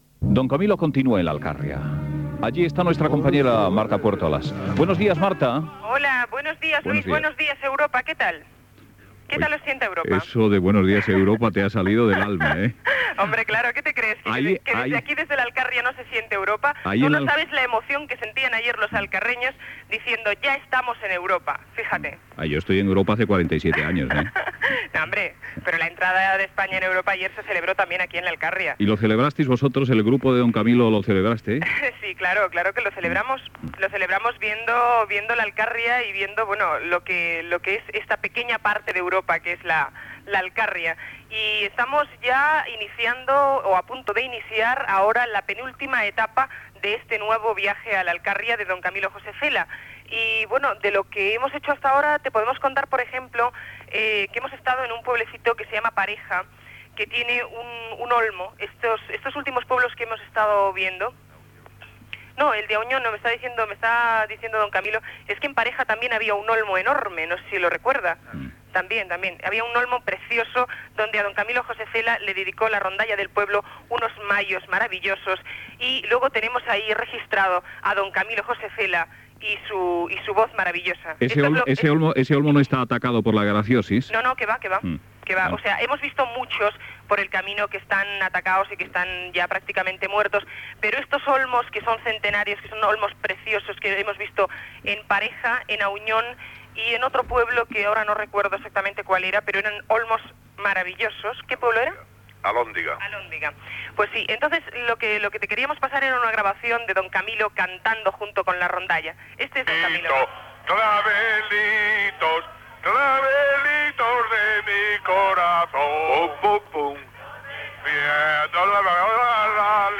S'escolten diverses cançons populars cantades per l'escriptor amb els veïns d'alguns pobles el dia anterior.
Info-entreteniment